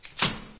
techage_valve.ogg